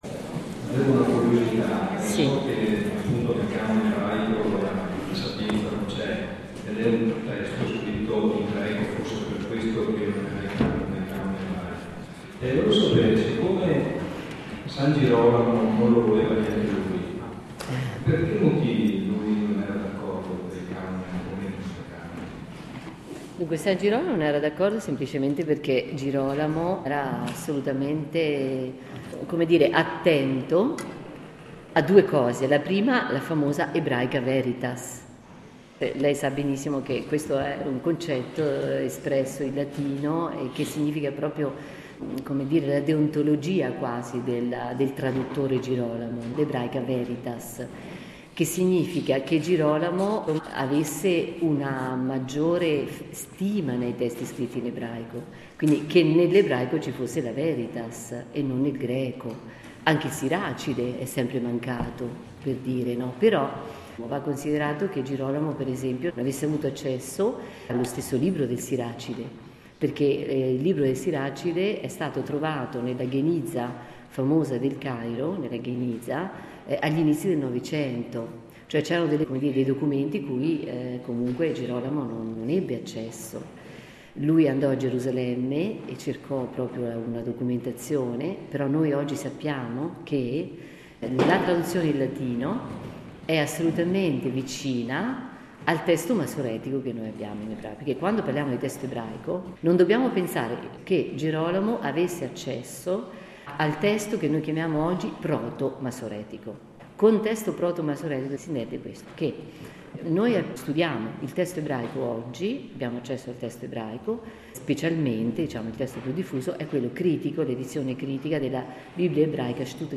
Palazzo Bo – Padova Ti consigliamo anche